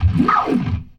GLISS 1.wav